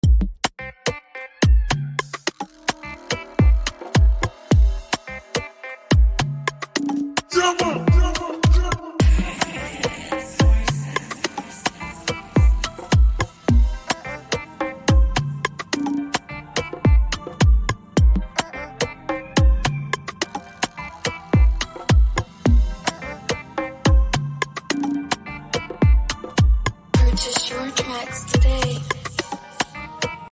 Afrobeat Instrumental